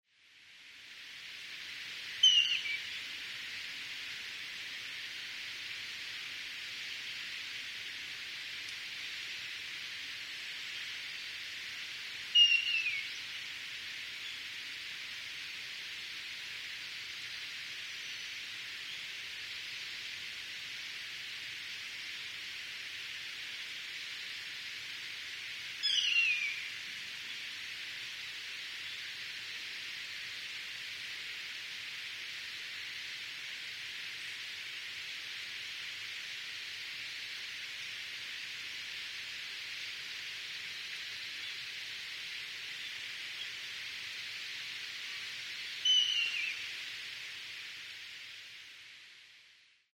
nosuri_c1.mp3